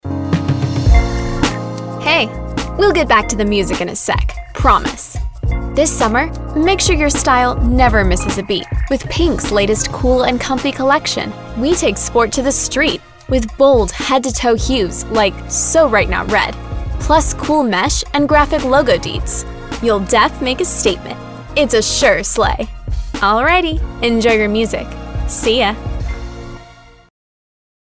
Female – Modern Style